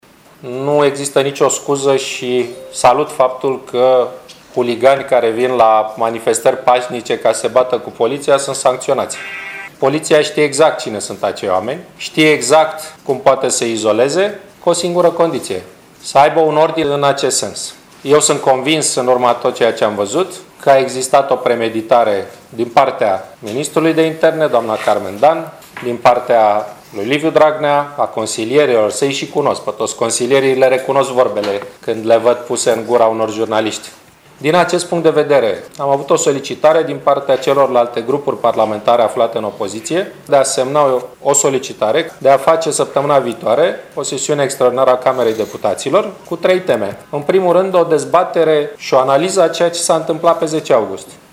Preşedintele ProRomânia, Victor Ponta, a susţinut astăzi o conferinţă de presă la Suceava.